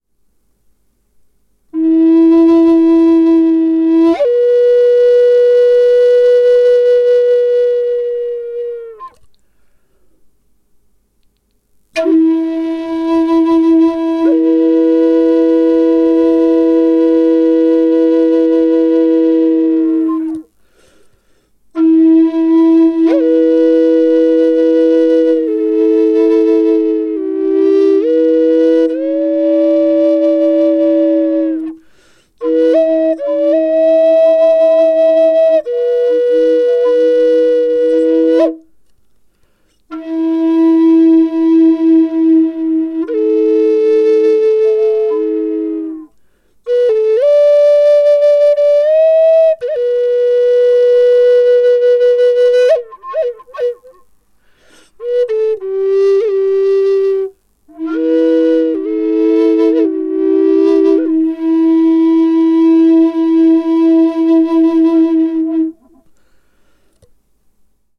E4 sävellajin Natiivihuilu double drone
Natiiviamerikkalaishuilu E4 sävellajissa. Matala ja maadoittava sointitaajuus. Viritetty pentatoniseen molli sävelasteikkoon.
Droneääni melodian taustalla luo upean, voimakkaan ja harmonisen tunnelman.
• Vire: Pentatoninen molli (440 hz tai 432 hz)
Ääninäyte ilman efektejä (dry):
E4_double-drone_DRY.mp3